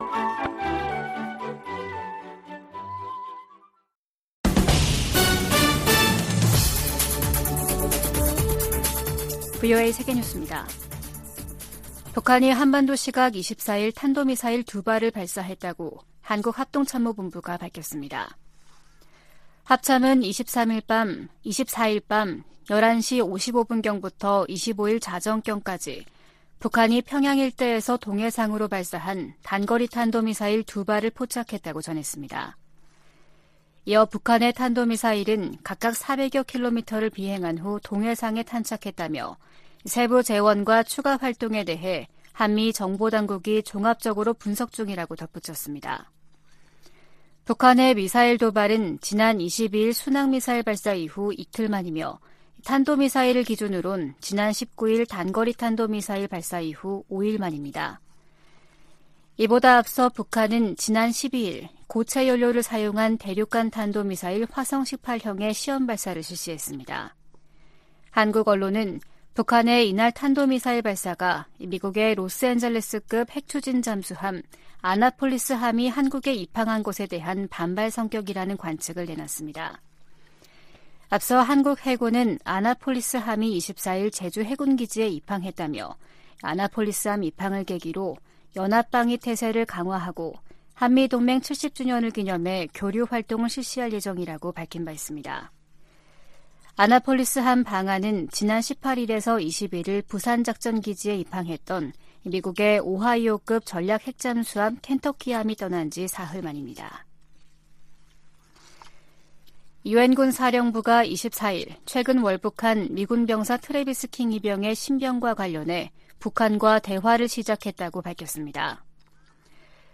VOA 한국어 아침 뉴스 프로그램 '워싱턴 뉴스 광장' 2023년 7월 25일 방송입니다. 북한은 지난 19일 동해상으로 단거리 탄도미사일(SRBM) 2발을 발사한데 이어 사흘 만에 다시 서해상으로 순항미사일 수 발을 발사했습니다. 미국은 전략핵잠수함의 부산 기항이 핵무기 사용 조건에 해당된다는 북한의 위협을 가볍게 여기지 않는다고 백악관이 강조했습니다. 유엔군사령부는 월북한 주한미군의 신병과 관련해 북한과의 대화를 시작했다고 공식 확인했습니다.